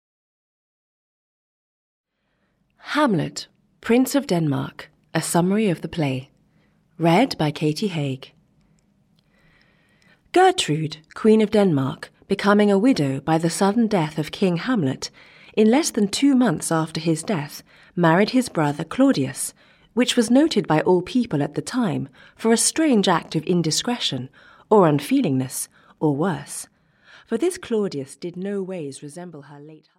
Audio knihaHamlet by Shakespeare, a Summary of the Play (EN)
Ukázka z knihy